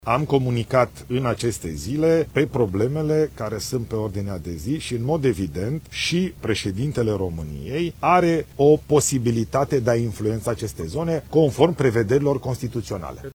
Și președintele României ar putea interveni, a mai spus Ilie Bolojan